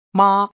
The 1st tone “ˉ” like in mā High and flat (